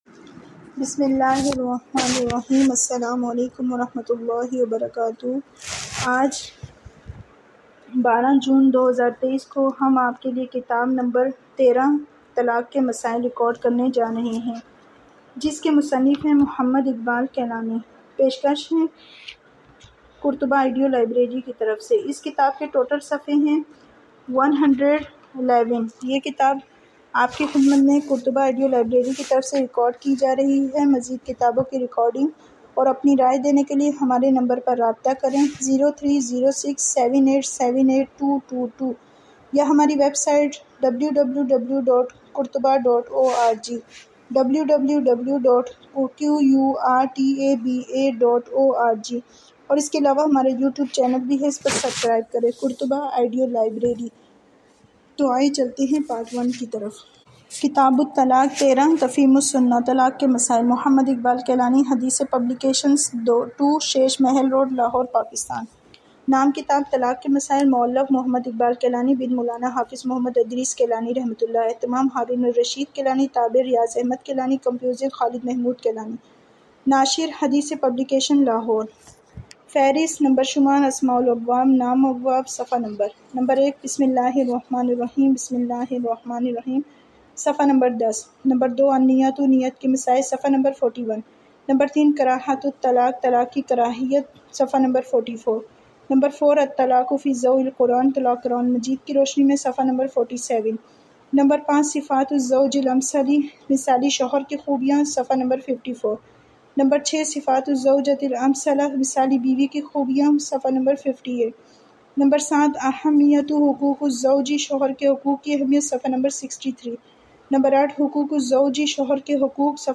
This audio book talaq k masail is written by a famous author mohammad iqbal kilani and it is listed under islamic category.